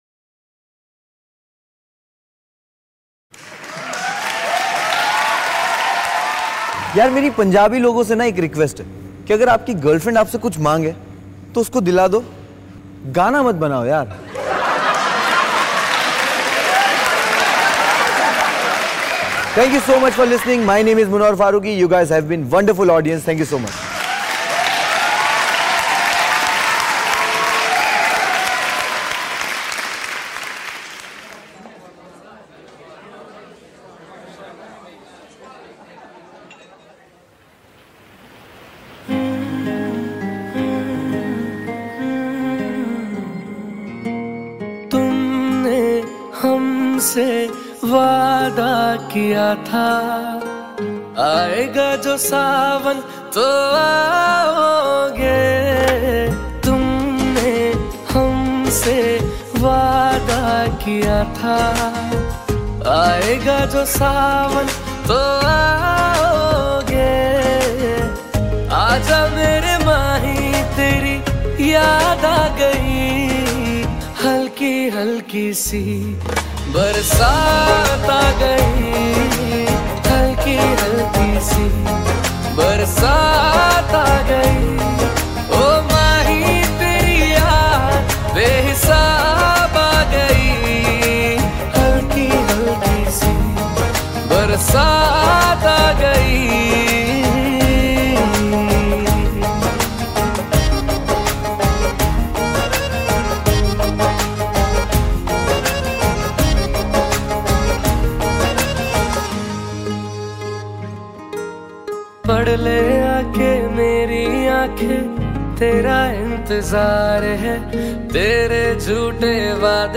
Categories Baarish Ringtones / Rain Ringtones